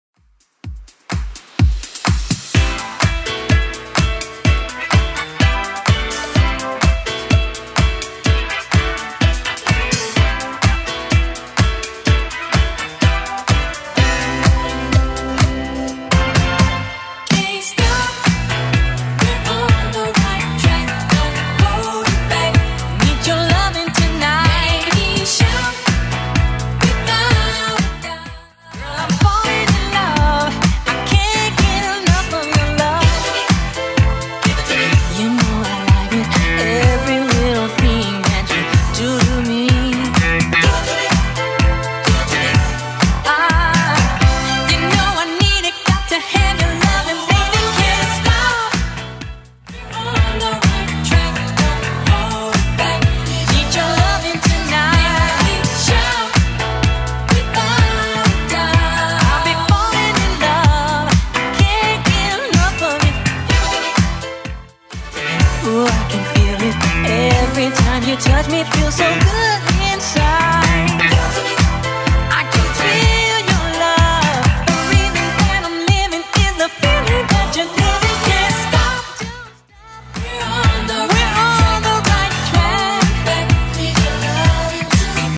Genre: 80's
BPM: 114